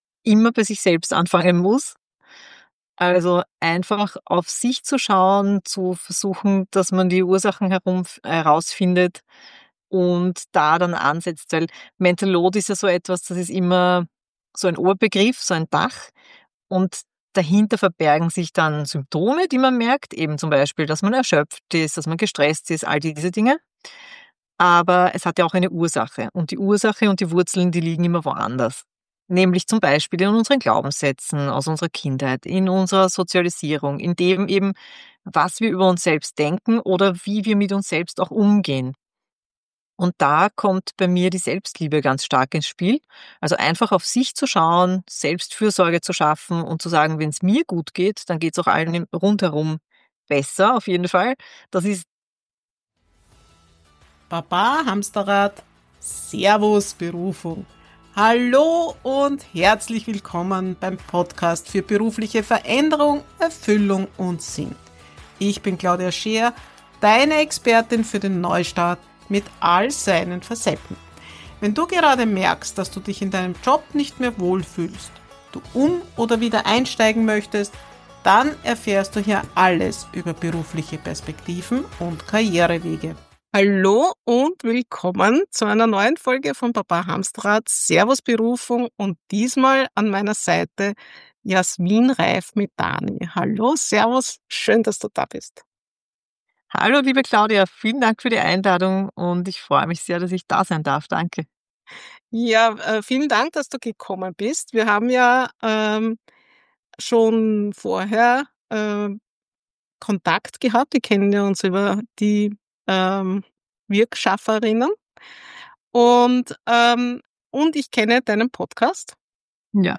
Ein inspirierendes Gespräch für alle, die das Gefühl kennen, immer alles im Kopf jonglieren zu müssen – und endlich lernen wollen, leichter und freier durchs Leben zu gehen.